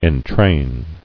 [en·train]